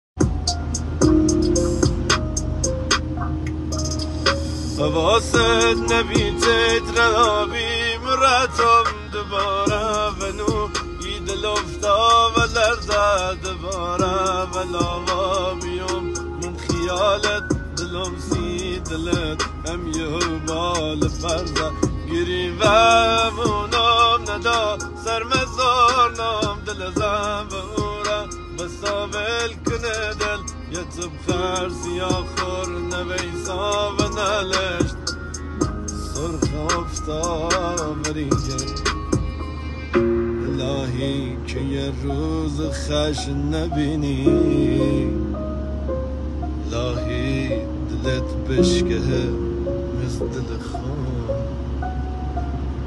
محلی عاشقانه لری